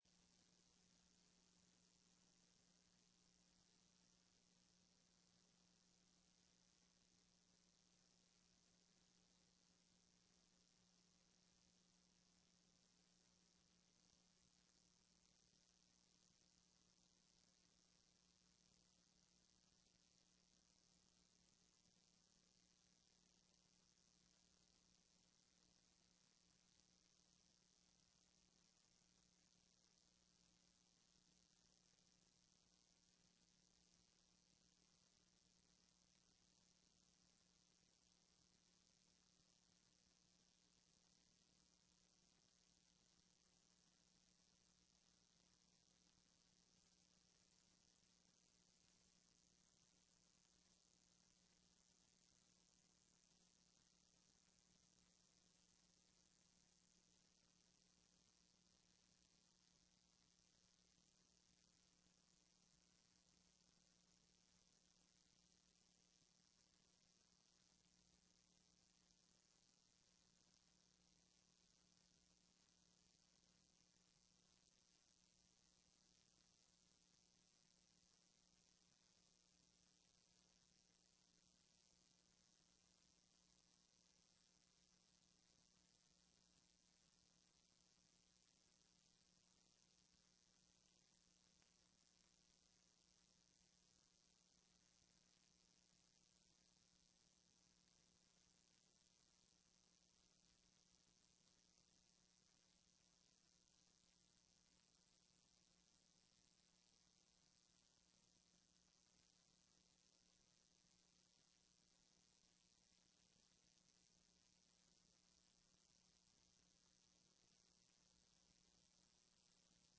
Sessão do dia 30/11/15